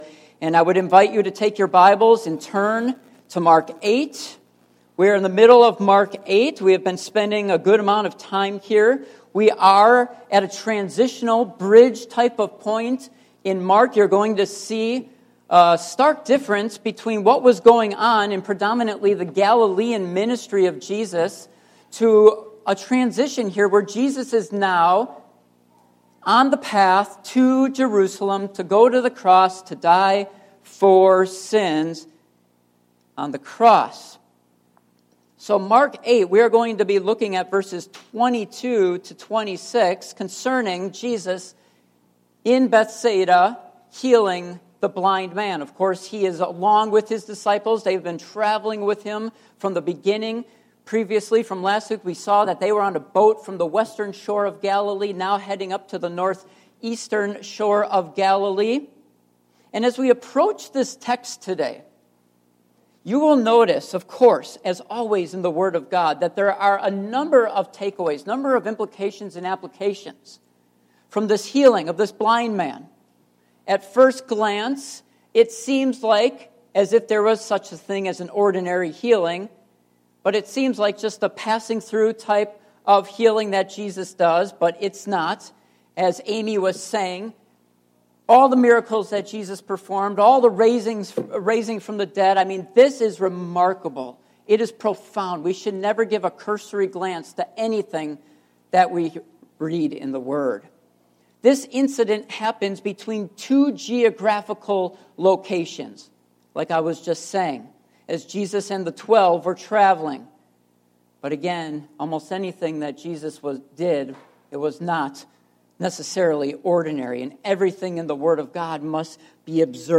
Mark Passage: Mark 8:22-26 Service Type: Morning Worship Topics